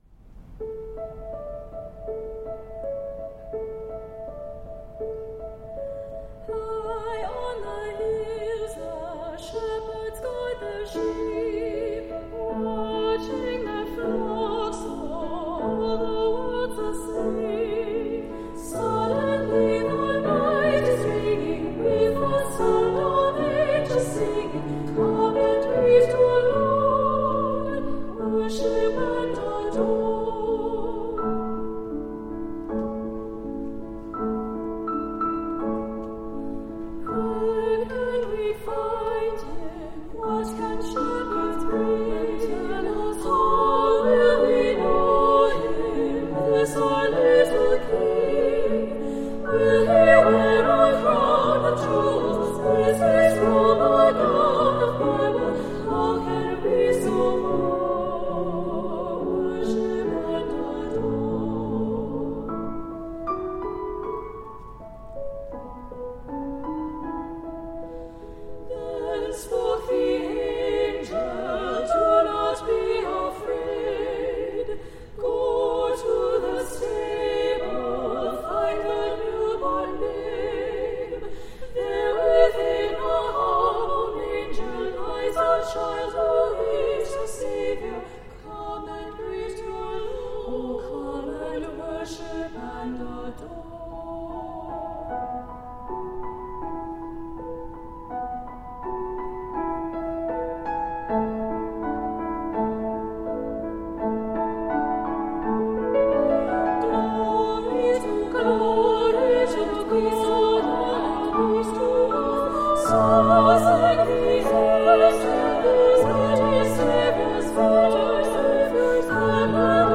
Voicing: SSA
Instrumentation: piano